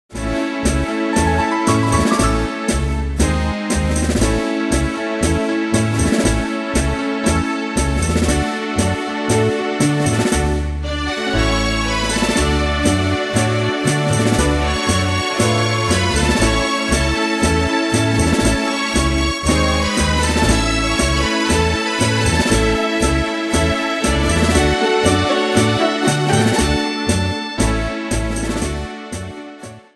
Style: Italie Ane/Year: 1934 Tempo: 118 Durée/Time: 3.08
Danse/Dance: Tango Cat Id.
Pro Backing Tracks